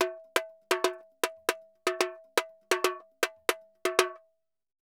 Tamborin Salsa 120_1.wav